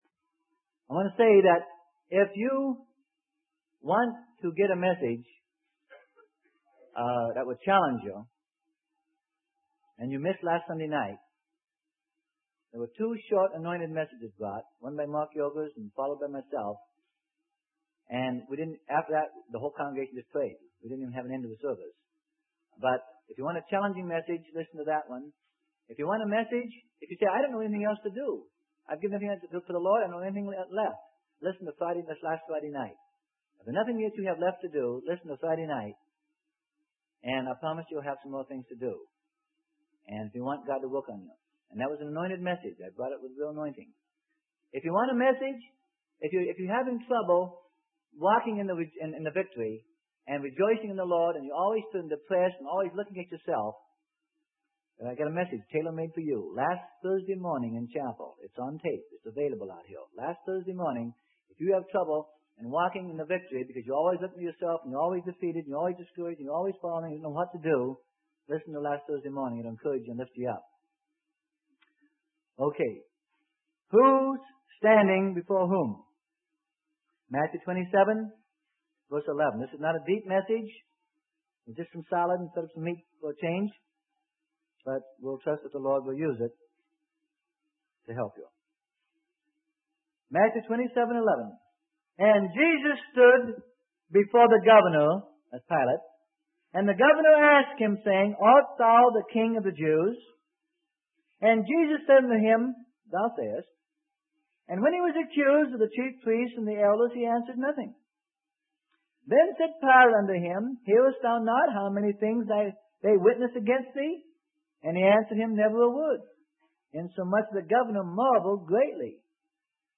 Sermon: Who is Standing Before Whom?